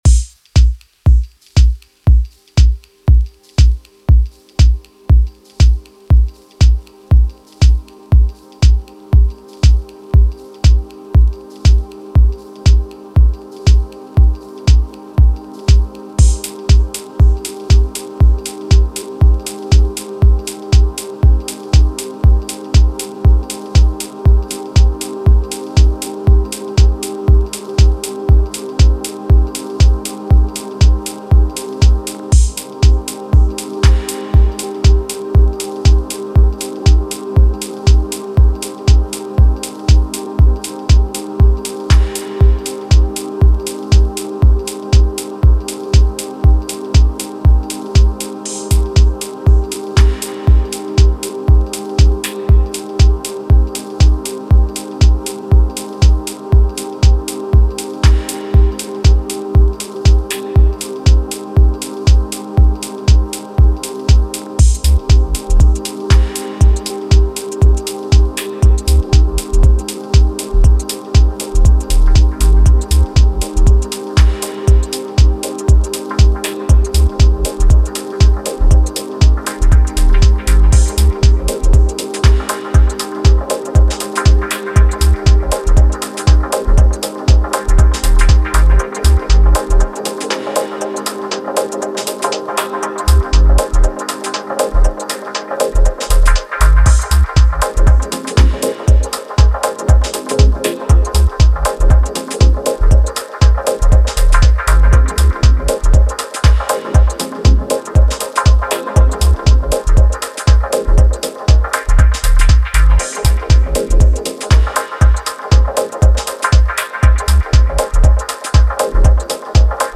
ずっしりと落ち着き払った低音と静かだが確実に高揚を運ぶコードでじっくりとグルーヴを紡ぐタイトルトラック